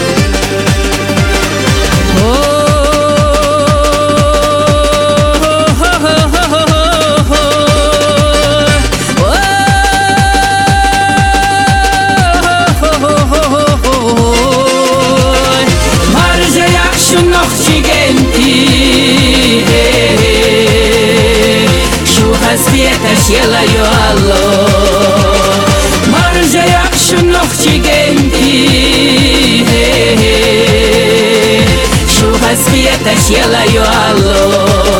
Жанр: Поп музыка / Русский поп / Русские
Pop